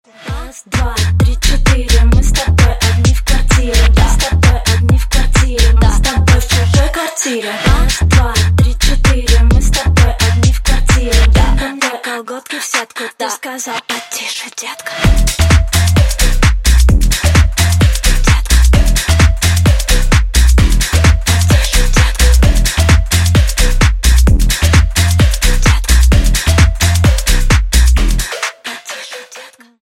Клубные Рингтоны
Поп Рингтоны